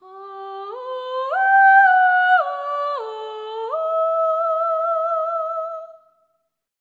ETHEREAL13-R.wav